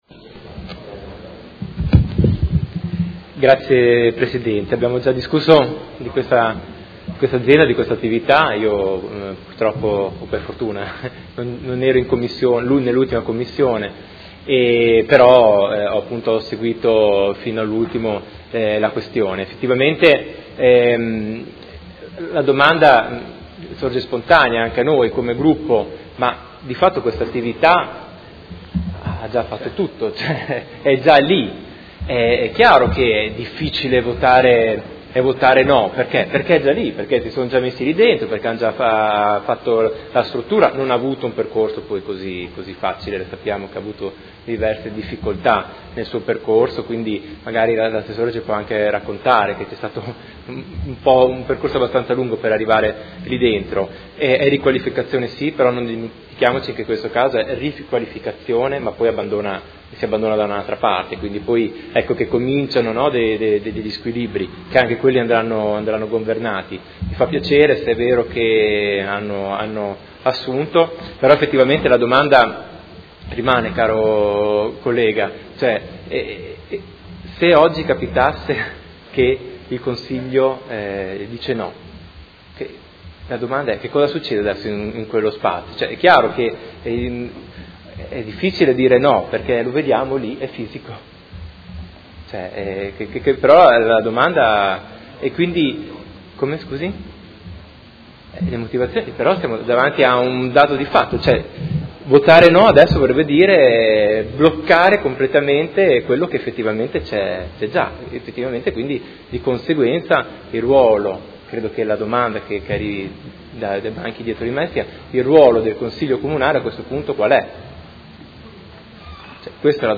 Seduta del 04/05/2017.